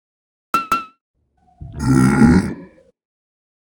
fire-2.ogg